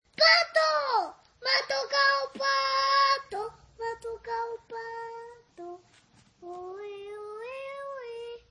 Redacción digital Madrid - Publicado el 22 ene 2017, 16:22 - Actualizado 17 mar 2023, 21:31 1 min lectura Descargar Facebook Twitter Whatsapp Telegram Enviar por email Copiar enlace El fan de Pato, feliz por el cromo que le ha tocado